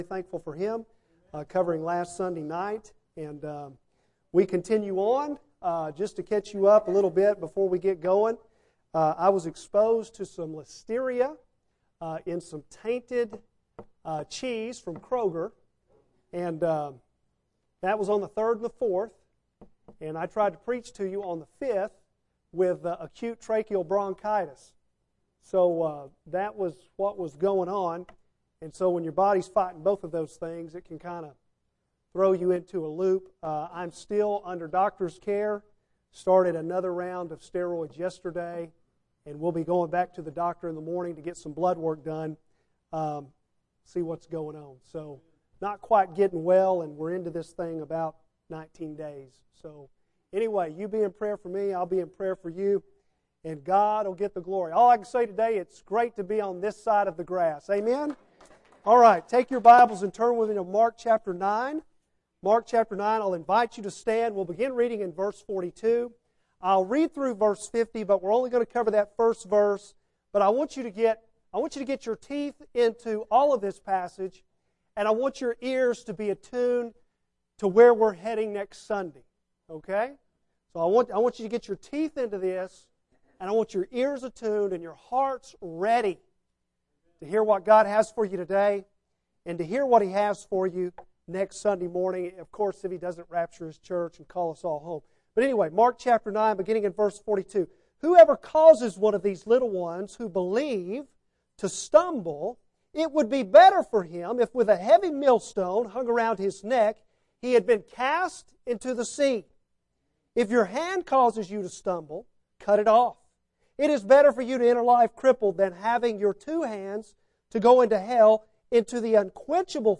Bible Text: Mark 9:42-50 | Preacher